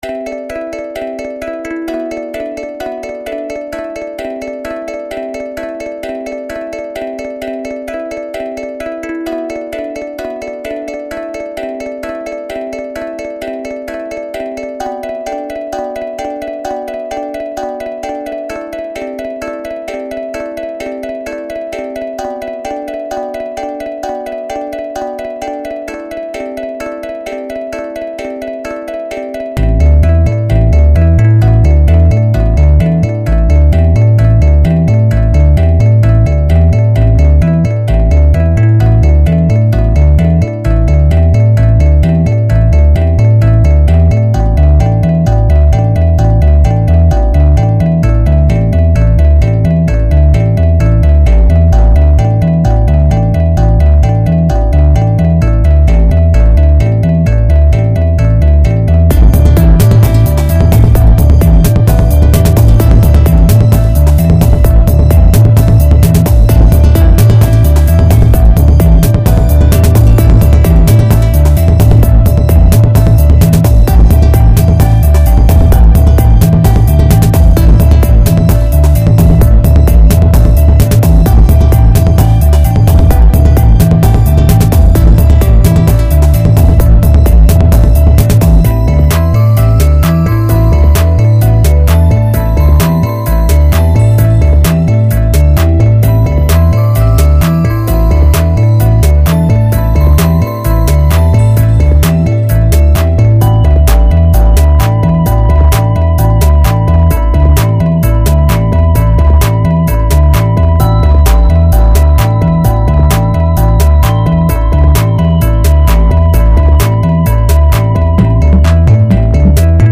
Countryside remix 2